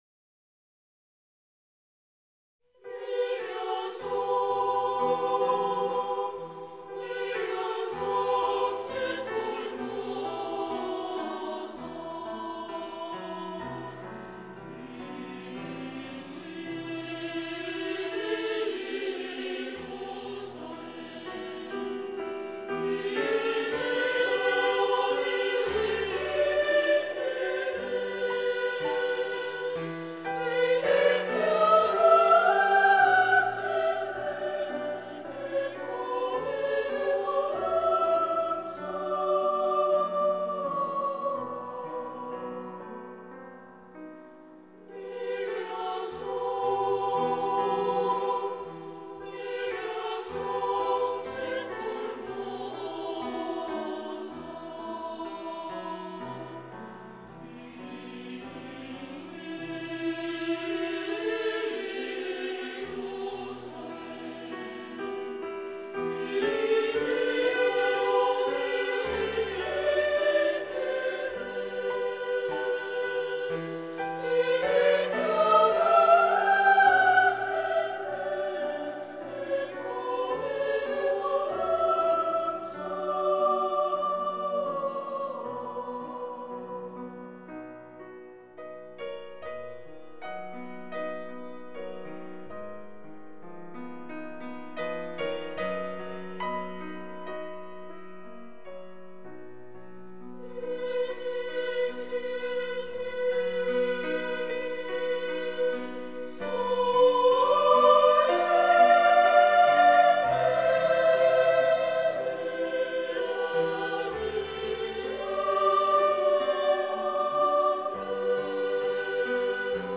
Orgue
Католический хор мальчиков